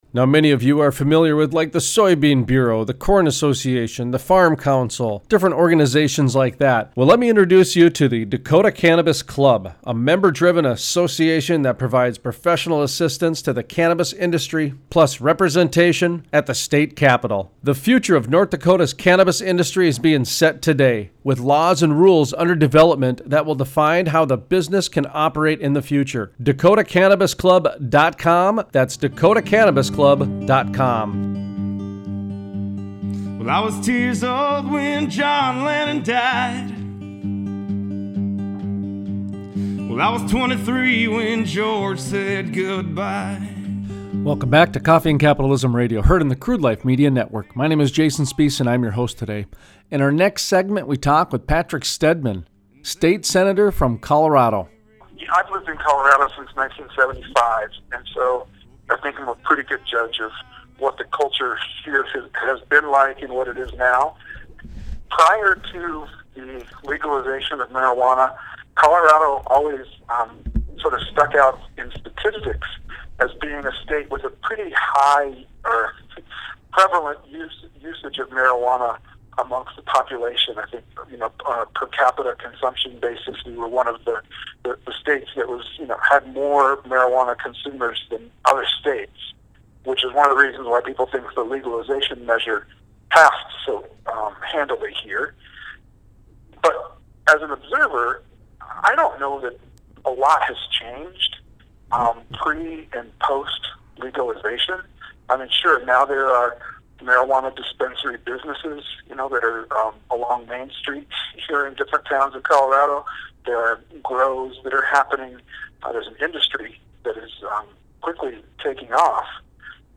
Interview: Patrick Steadman, State Senator Steadman explains life and culture in Colorado during the medical marijuana days and how it transitioned into recreational. He also explains how many jobs the new industry has created for the state.